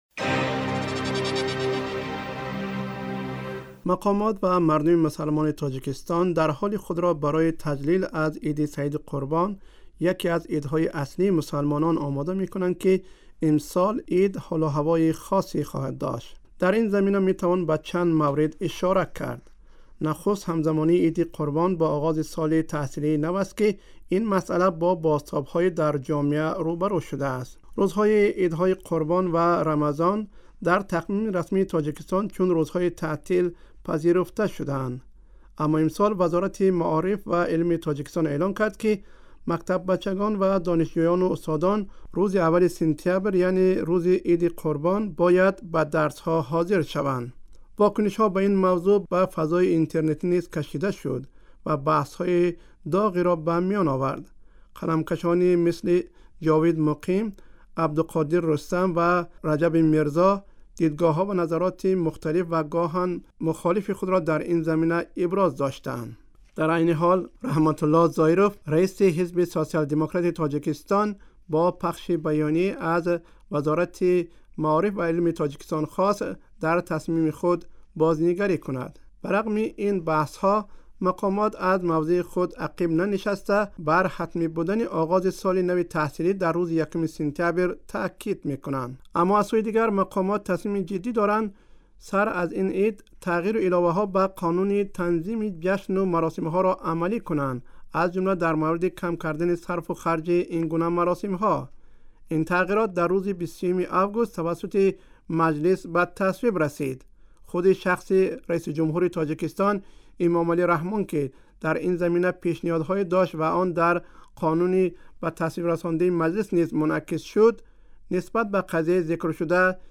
Шароити хосси Иди Қурбон 2017 дар Тоҷикистон (гузориши вижа)